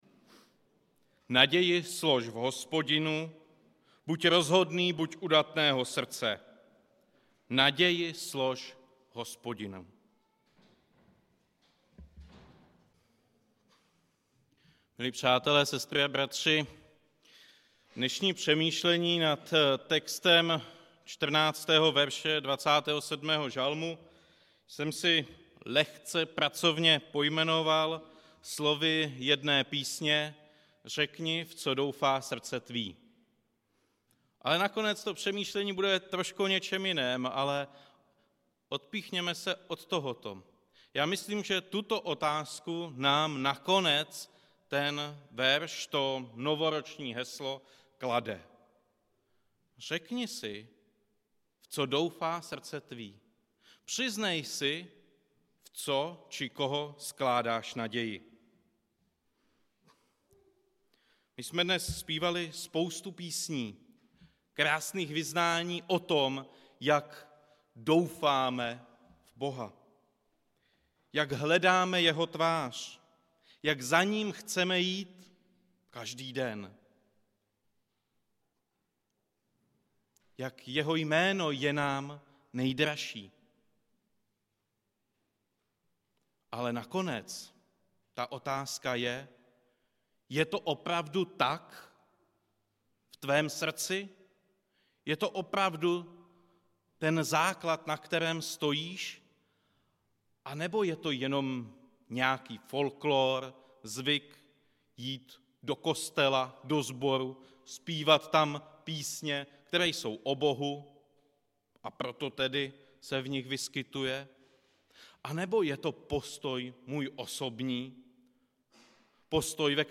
Událost: Kázání
Místo: Římská 43, Praha 2